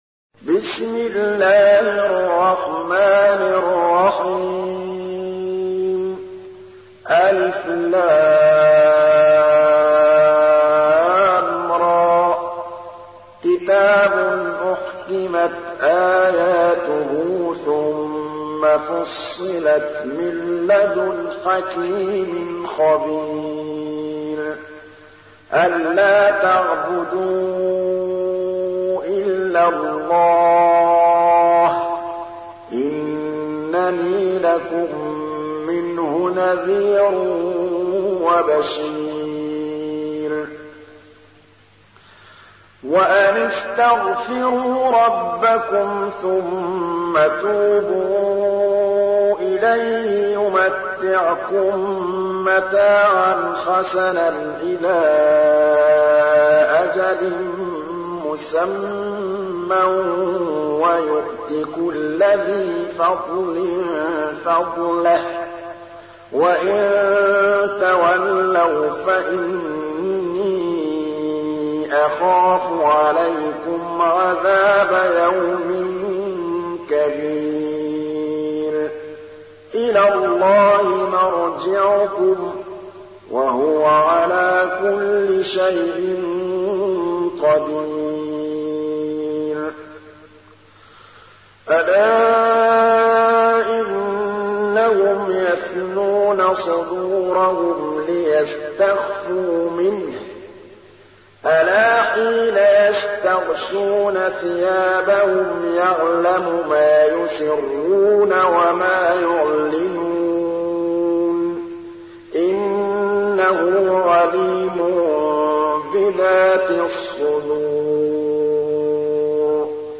تحميل : 11. سورة هود / القارئ محمود الطبلاوي / القرآن الكريم / موقع يا حسين